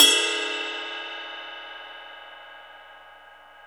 CYM XRIDE 4C.wav